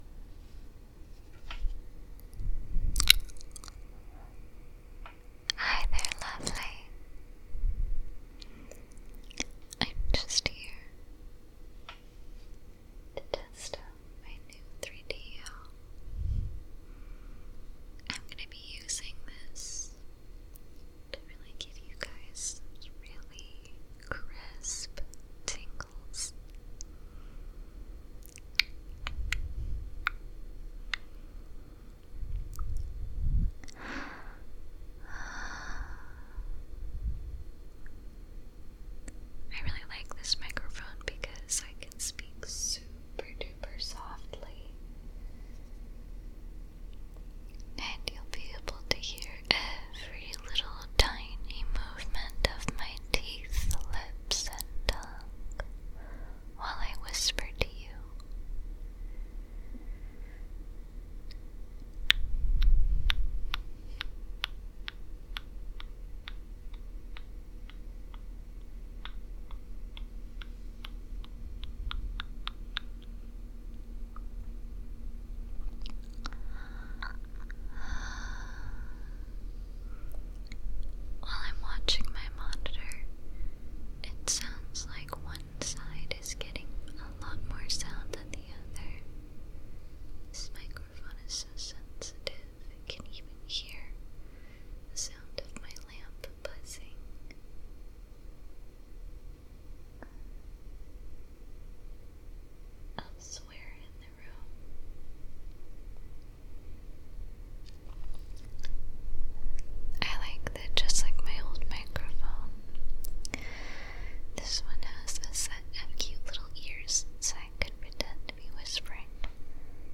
- audiogram.mp4 Content BINAURAL BEN IS DEAD LONG LIVE BINAURAL BEN But the time has come to crown a new king Please join me in welcoming Binaural Bartholomew Files